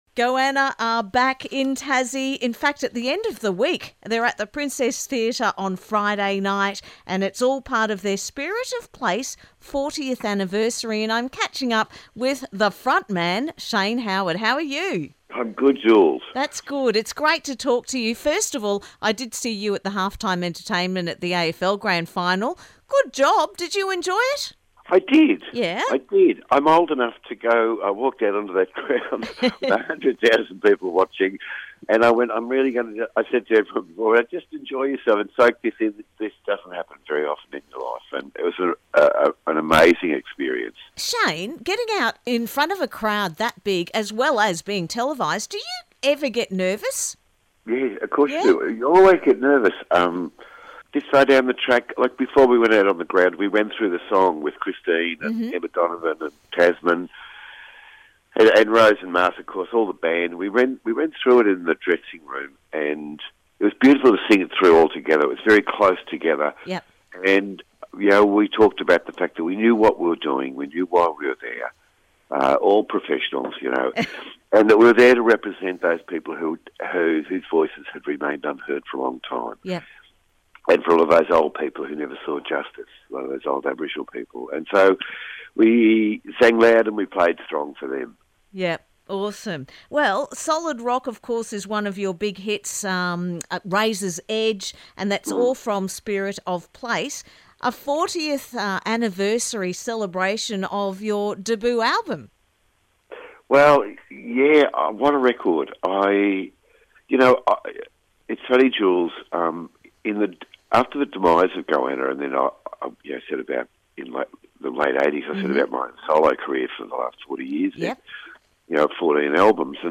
Goanna is performing at the Princess Theatre this Friday night - I caught up with front man Shane Howard for a chat today.